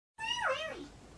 wiwiwi kitten Meme Sound Effect
wiwiwi kitten.mp3